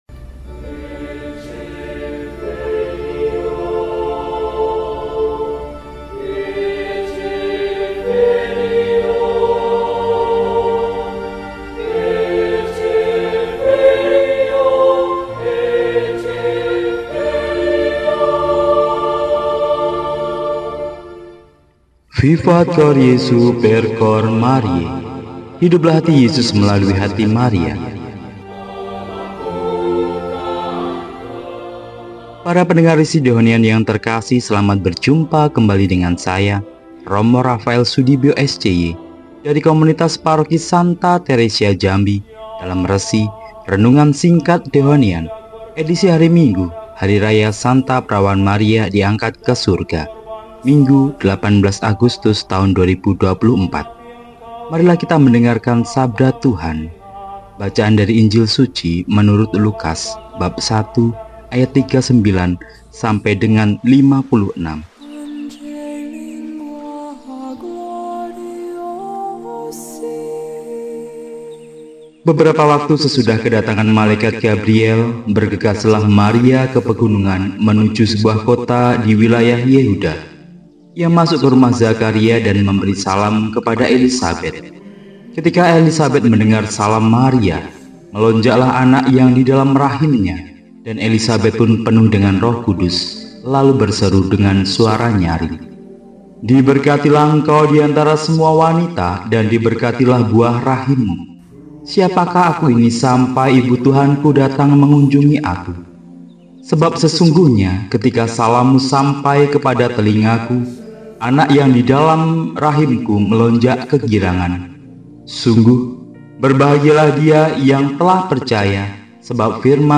Minggu, 18 Agustus 2024 – Hari Raya SP Maria Diangkat ke Surga – RESI (Renungan Singkat) DEHONIAN